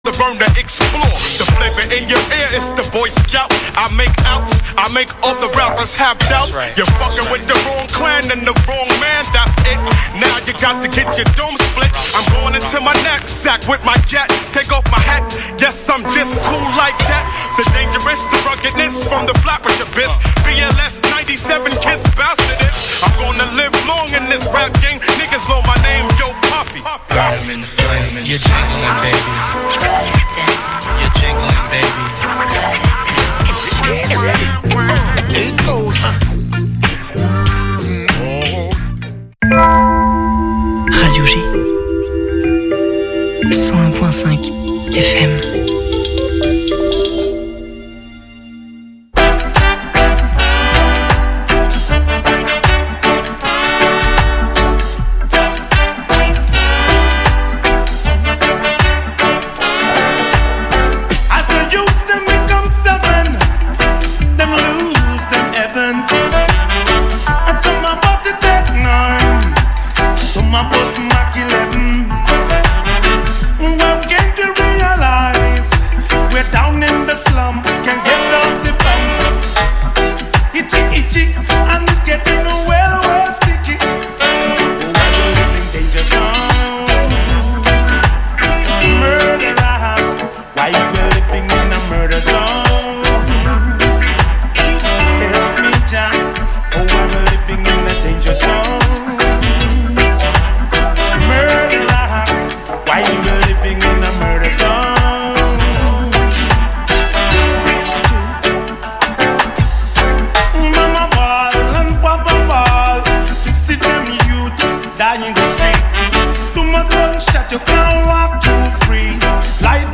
Emission sportive locale et nationale